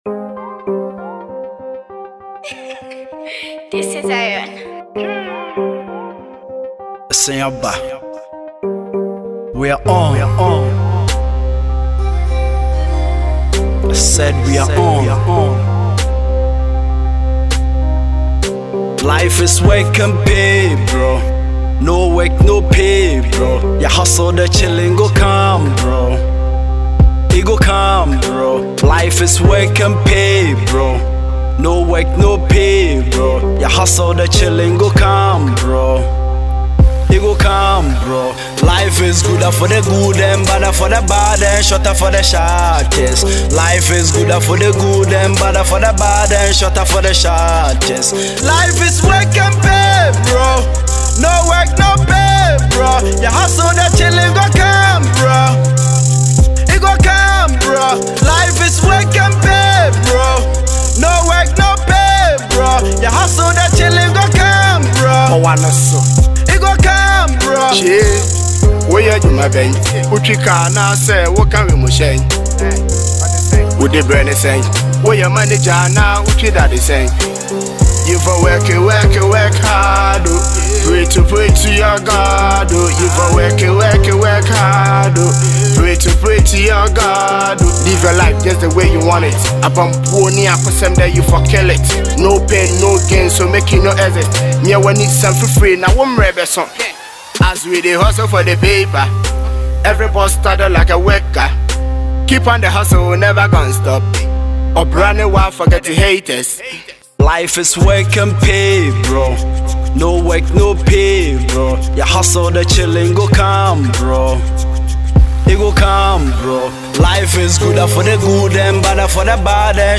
motivational tune